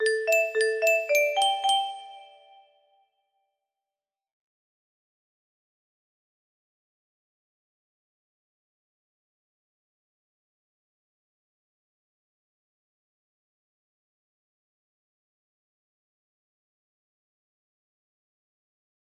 Ashlynn music box melody